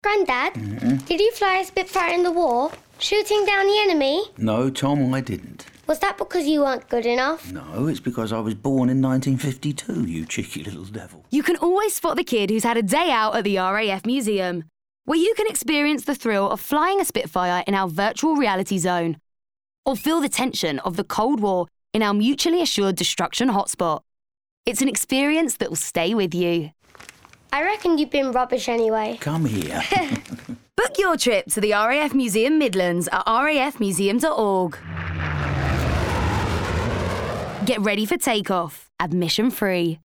The sonic ident reflected the history and longevity of the RAF – by gradually morphing the sound of a spitfire-esque plane into that of a modern fighter jet.